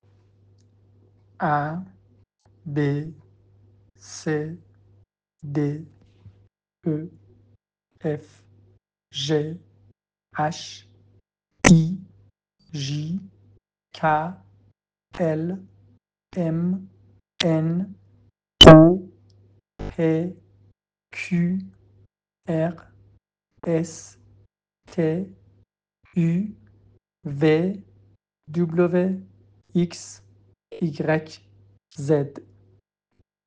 تلفظ الفبای فرانسه
IFEL-alphabet.mp3